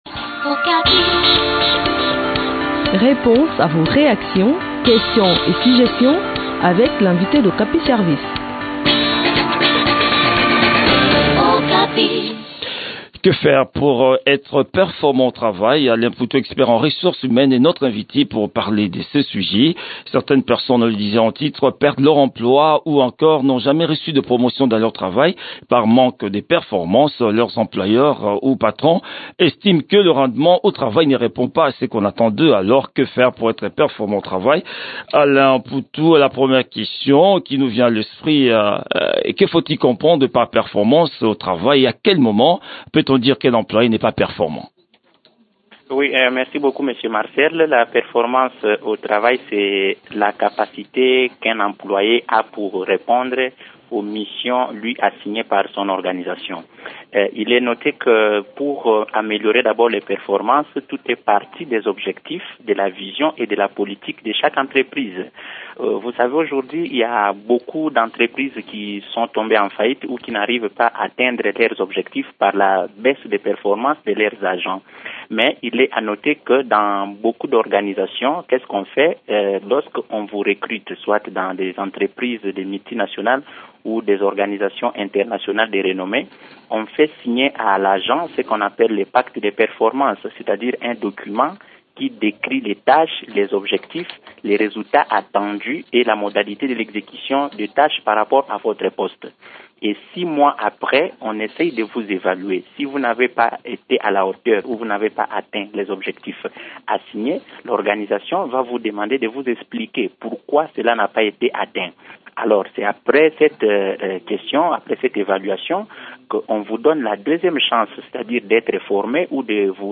expert en ressources humaines.